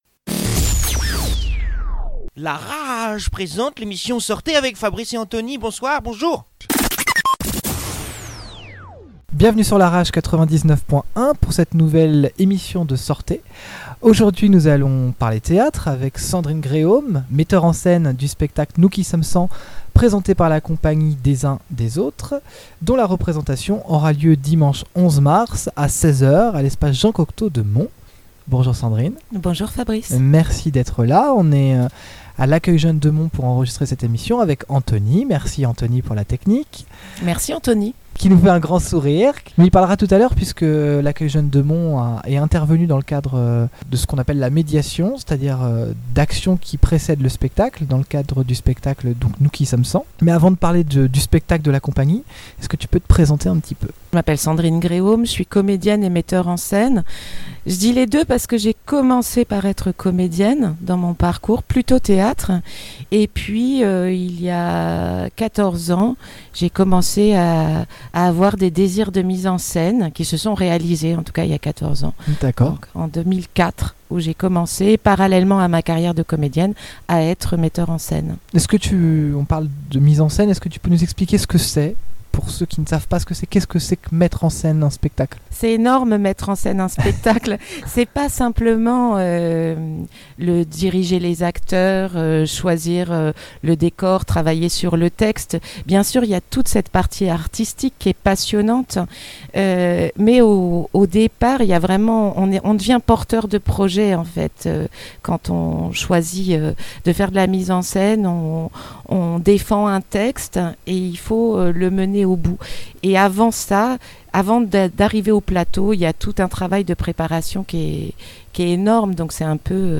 enregistrée en plein coeur de l’Accueil Jeunes Monts